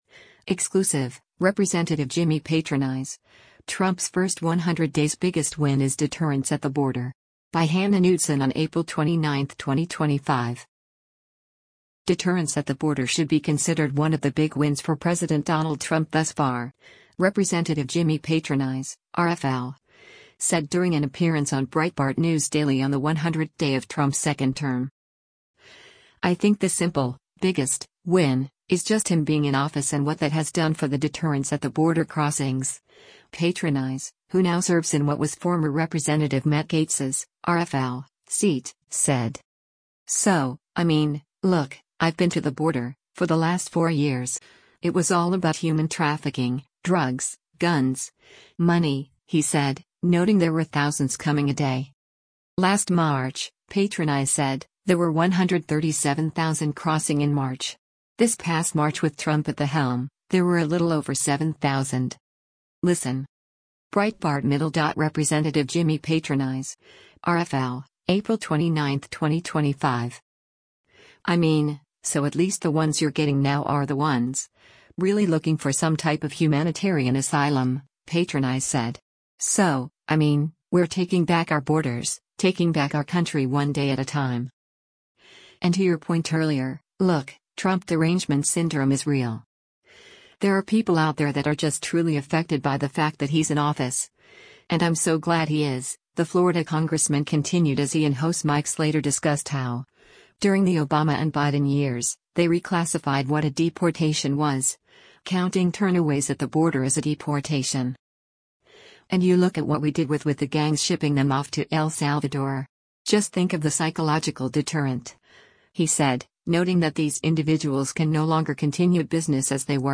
Deterrence at the border should be considered one of the big wins for President Donald Trump thus far, Rep. Jimmy Patronis (R-FL) said during an appearance on Breitbart News Daily on the 100th day of Trump’s second term.